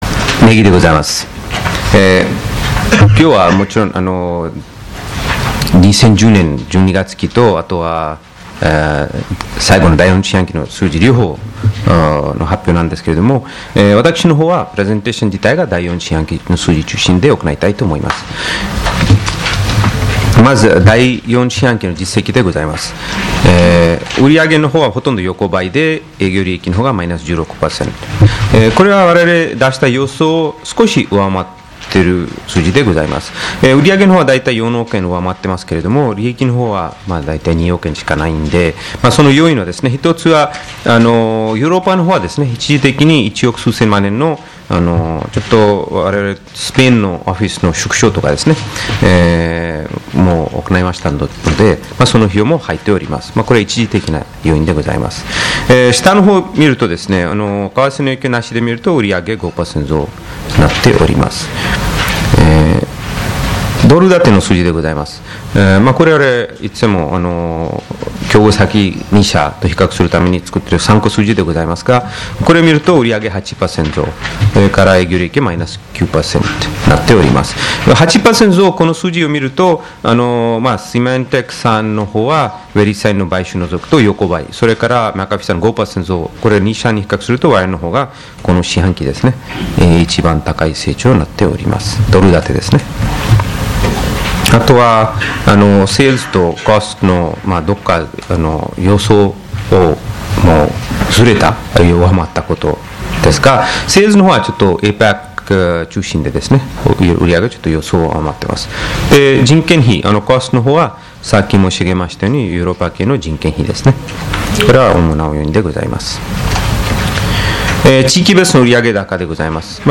決算説明会の音声ファイル